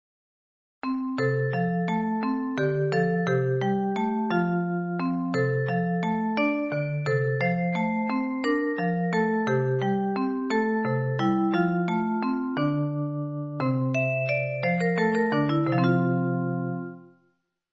Classical and popular music box songs